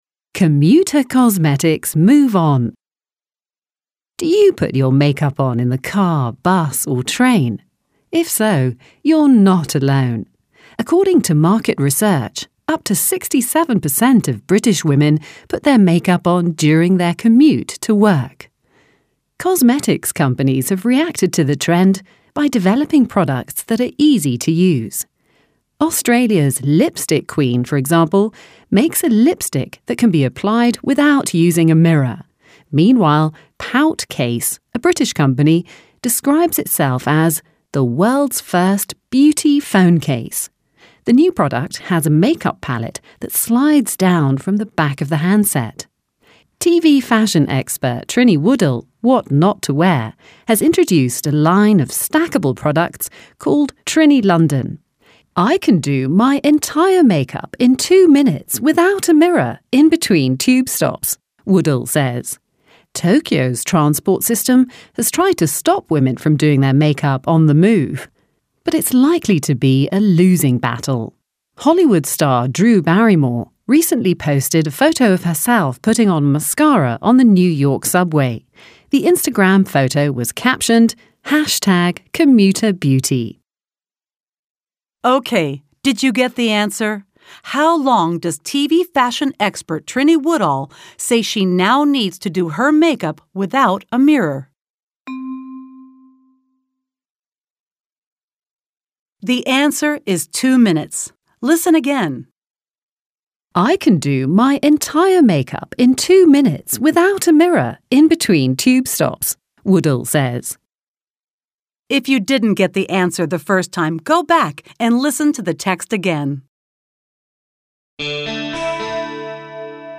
Audio-Übung